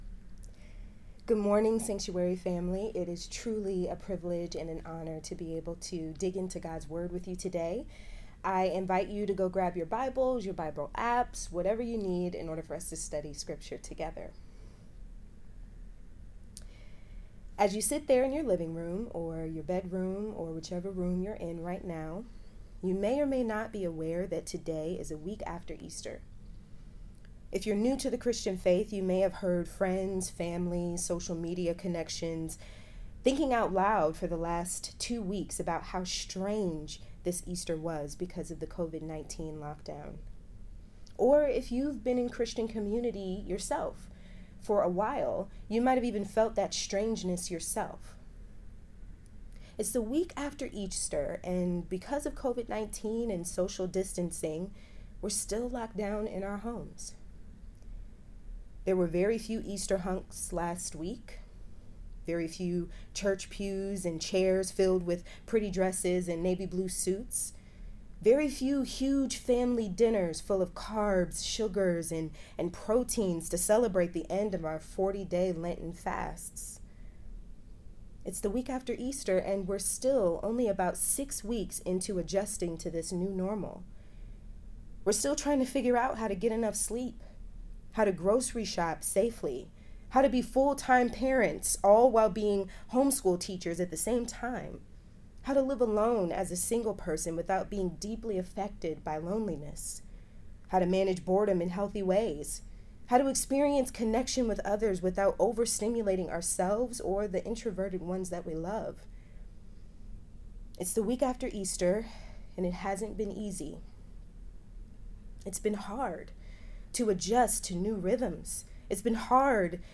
Sermons | Sanctuary Columbus Church